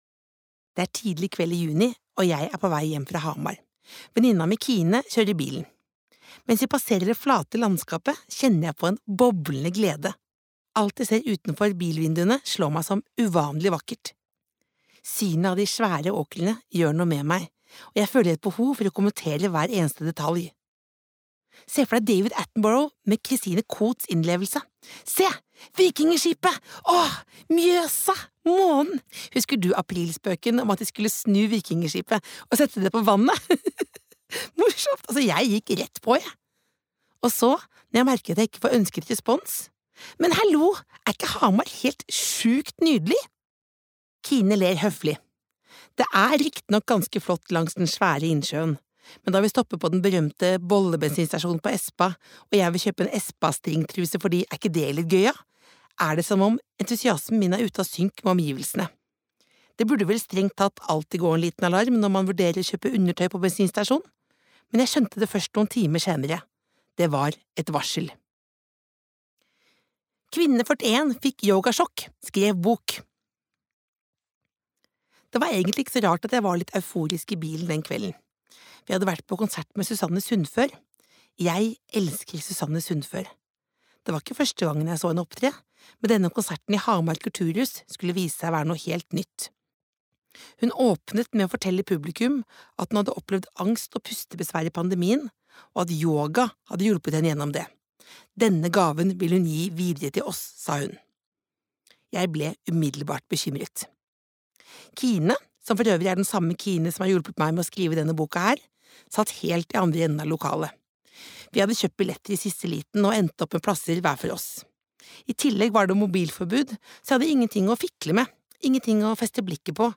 Jeg burde kanskje sagt nei (lydbok) av Else Kåss Furuseth